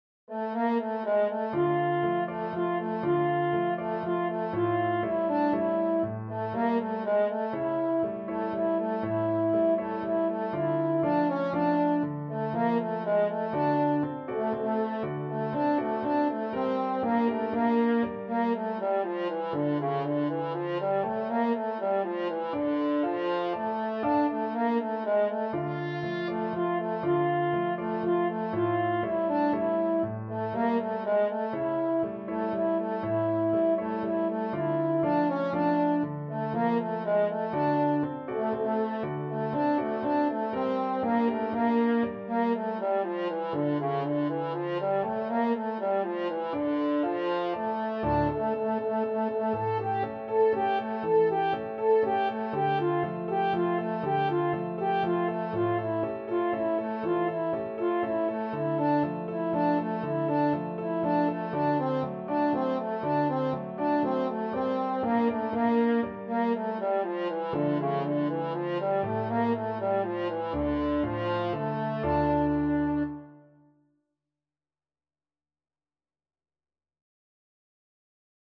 French Horn
D minor (Sounding Pitch) A minor (French Horn in F) (View more D minor Music for French Horn )
3/4 (View more 3/4 Music)
Db4-A5
Traditional (View more Traditional French Horn Music)
world (View more world French Horn Music)